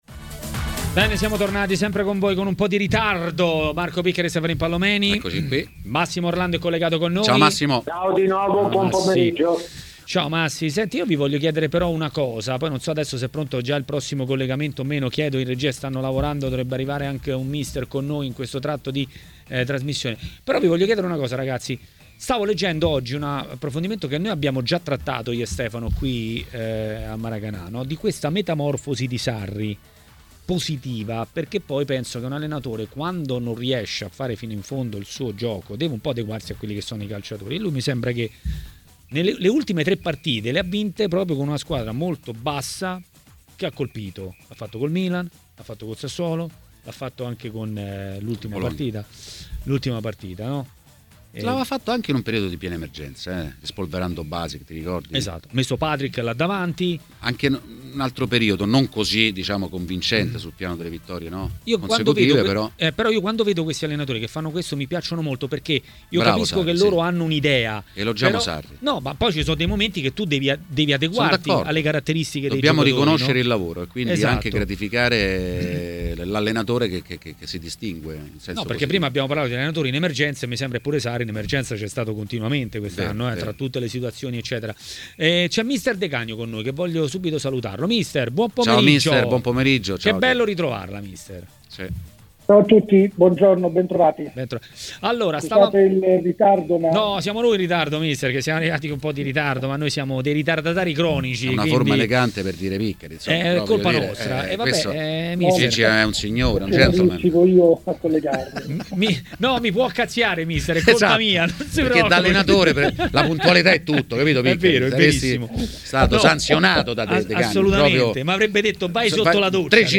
Ospite di Maracanà, trasmissione di TMW Radio, è stato mister Gigi De Canio.